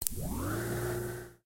磁带异常 " TAP E31
描述：最初是1971年用廉价设备在嘈杂的环境中录制的。
录音机开关和卷轴式磁带开始加速。
标签： 记录器 卷到卷轴 磁带